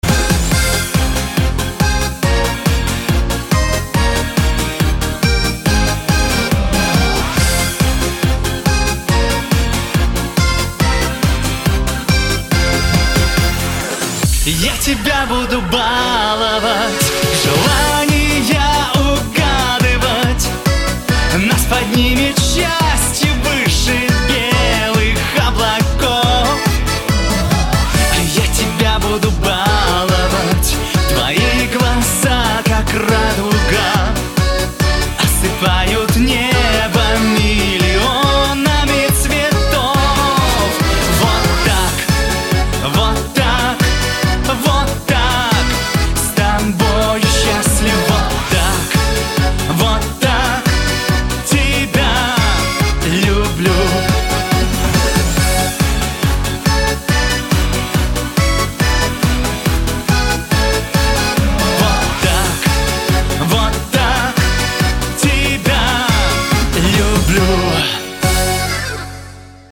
• Качество: 224, Stereo
мужской вокал
громкие
заводные
русский шансон